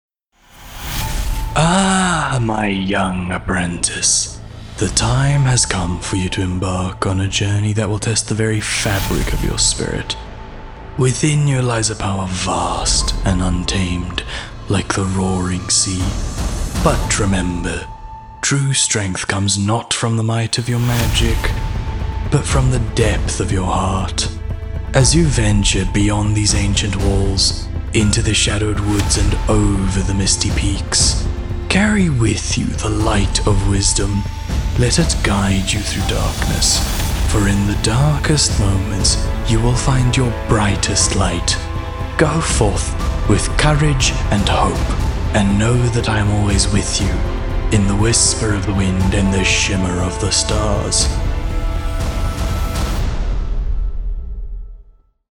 animation, articulate, authoritative, character, Deep
Character- Wise Wizard